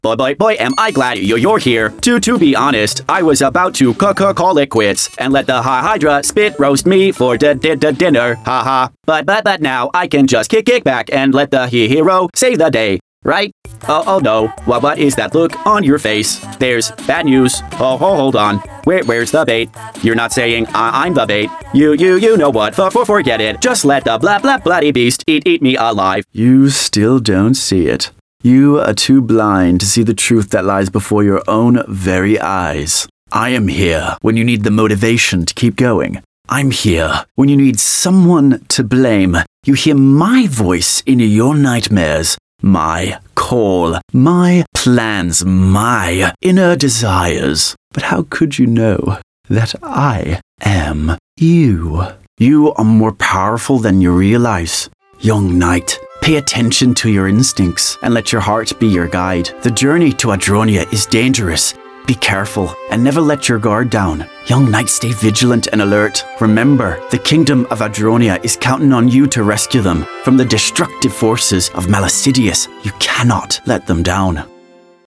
Male Voice Over Artists Actors & Talent for Hire Online
Yng Adult (18-29)